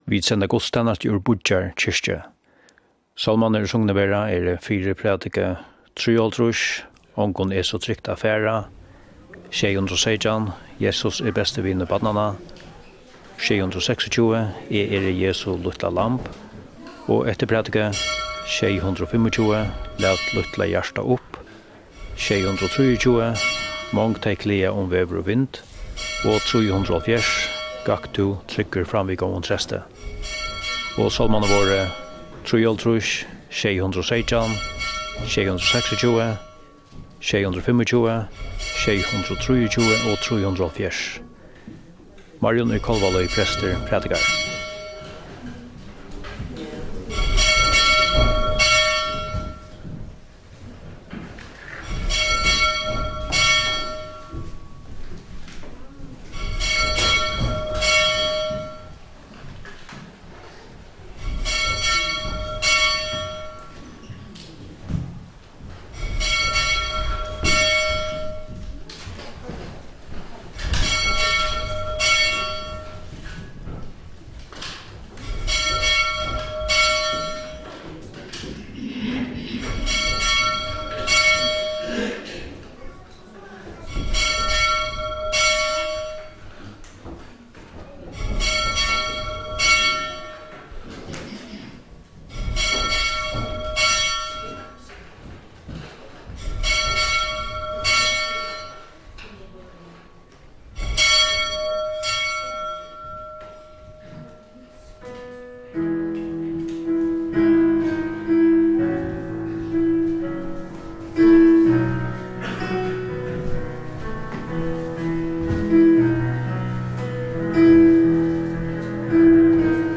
1 Allahalgannaguðstænasta úr Havnar kirkju 57:48